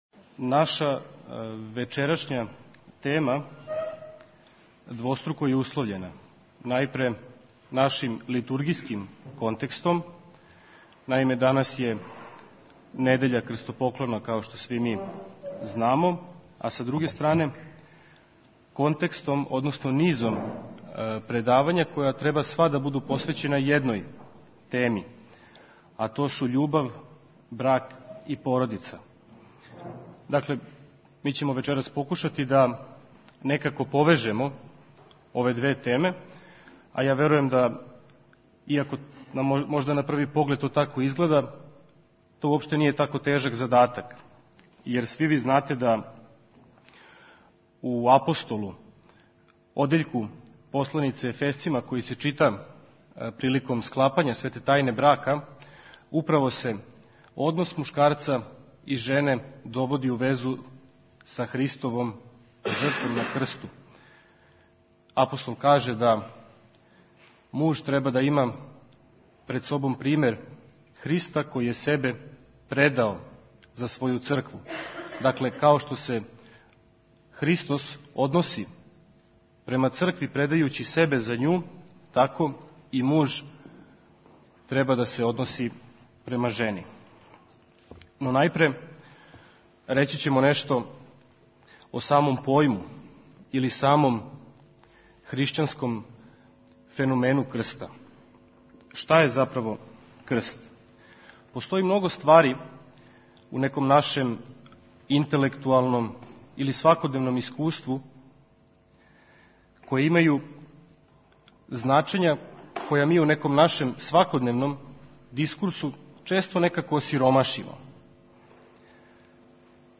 Учешћa у Трибини узео је и Његово Преосвештенство Епископ бачки Господин др Иринеј.
Звучни запис предавања